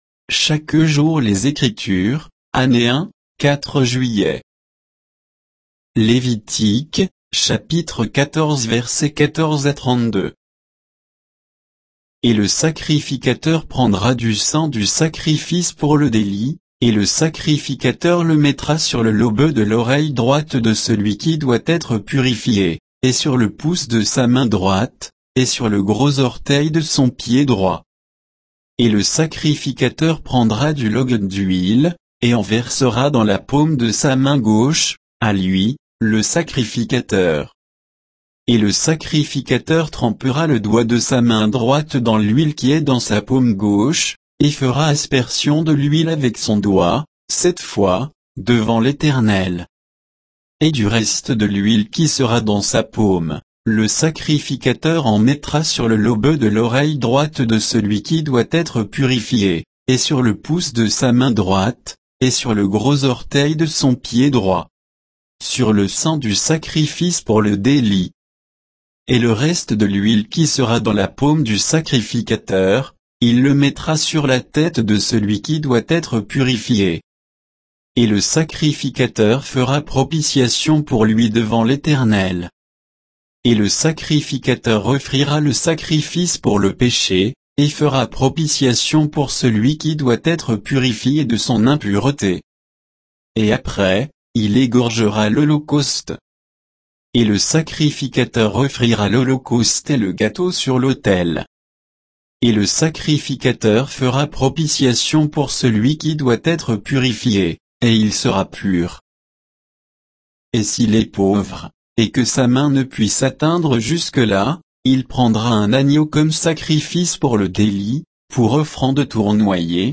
Méditation quoditienne de Chaque jour les Écritures sur Lévitique 14, 14 à 32